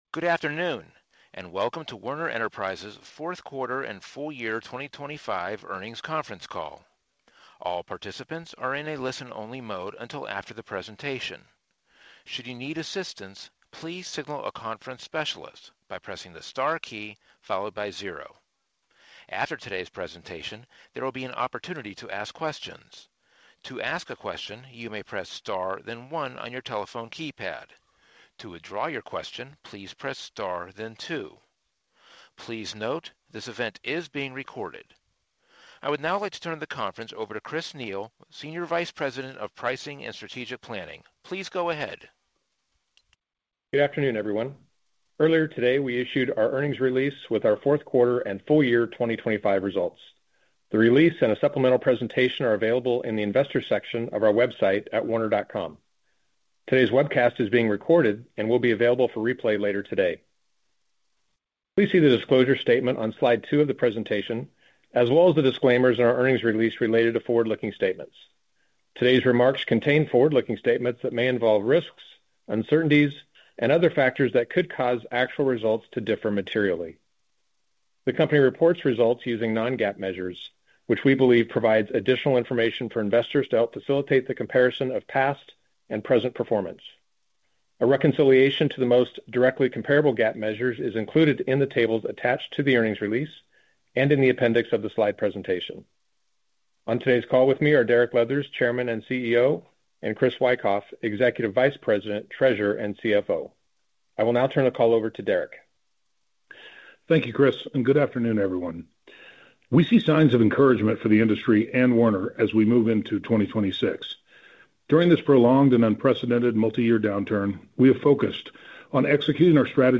WERN-4Q25-Earnings-Conference-Call.mp3